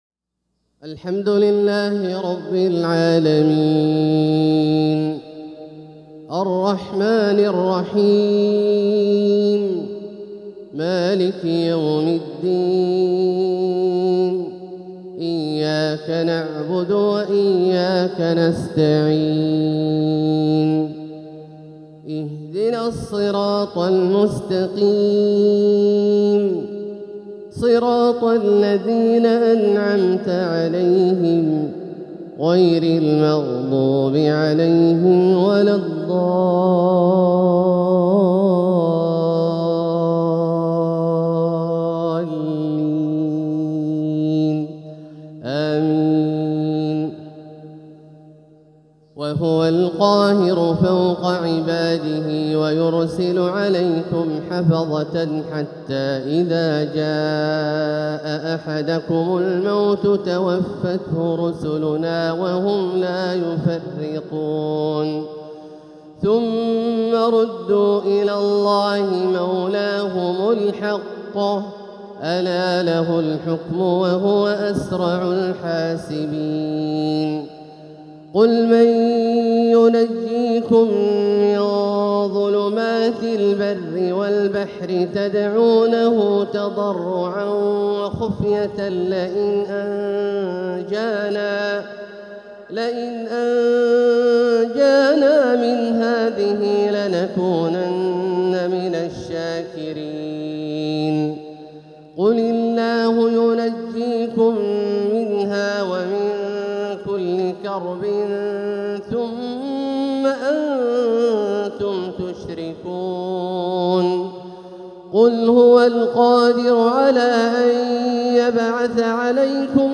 تلاوة من سورة الأنعام 61-73 | فجر السبت 8-6-1447هـ > ١٤٤٧هـ > الفروض - تلاوات عبدالله الجهني